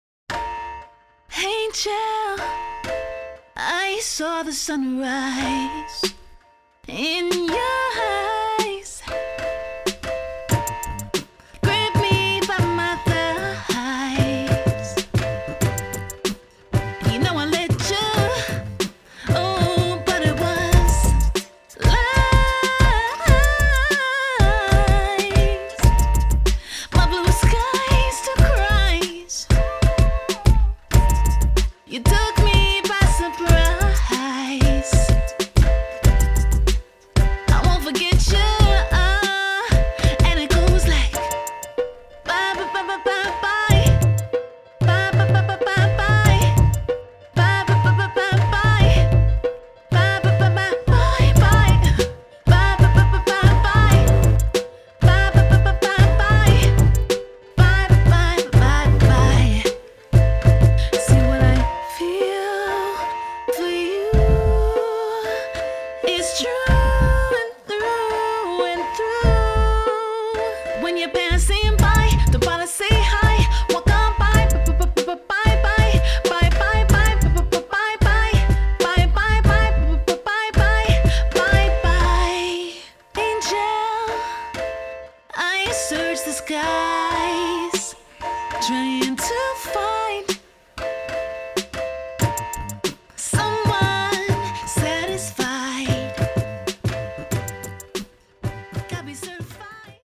[ROUGH DEMO SNIPPET]
R&B singer
This song has all the makings of a cult classic R&B song.
the simple trailing piano chord, the ‘beat boxing’